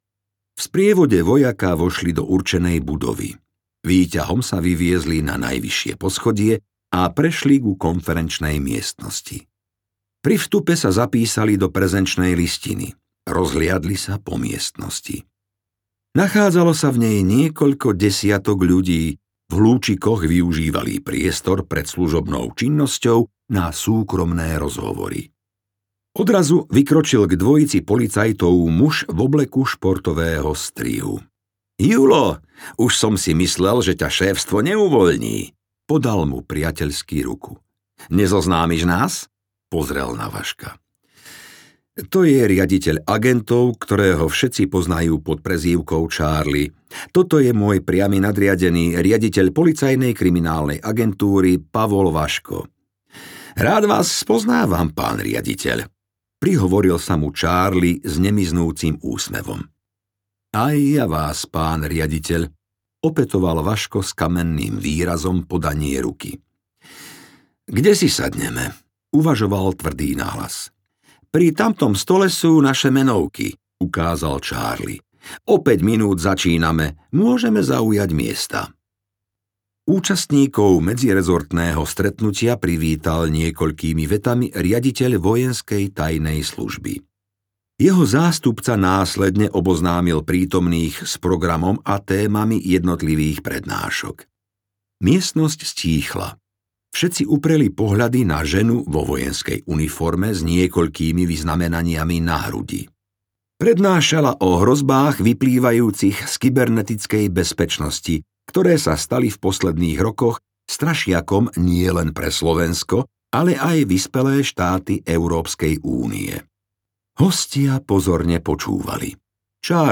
Rozsudok smrti audiokniha
Ukázka z knihy